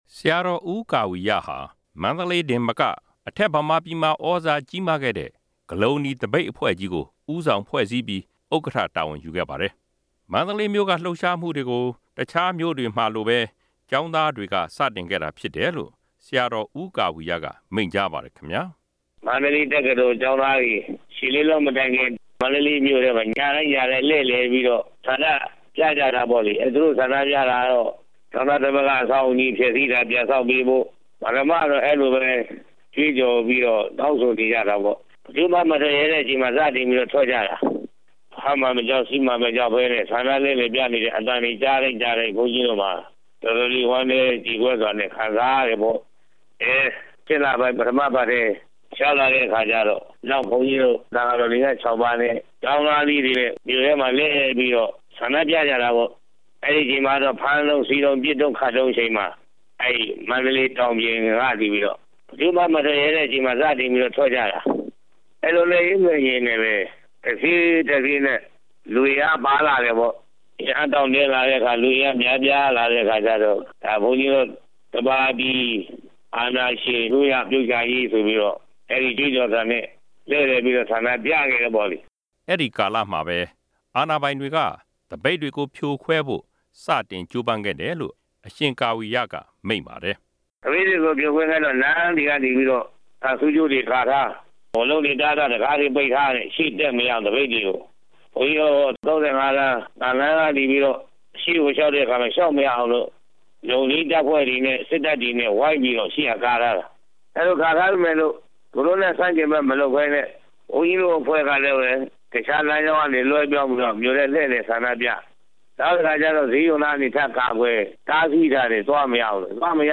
မိန့်ကြားချက်